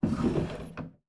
drawer_open.wav